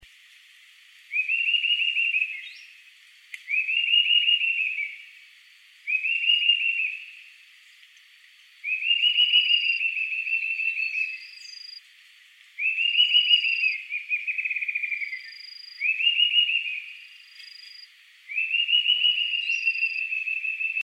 Gritador (Sirystes sibilator)
Nome em Inglês: Sibilant Sirystes
Localidade ou área protegida: Reserva Privada y Ecolodge Surucuá
Condição: Selvagem
Certeza: Gravado Vocal
SUIRIRI-SILBON-2.MP3